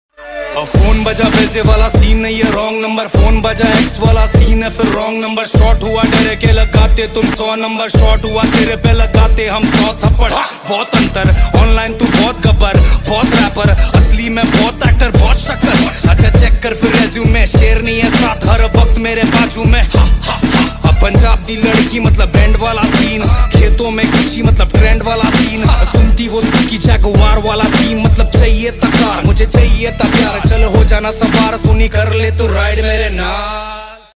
new rap song ringtone download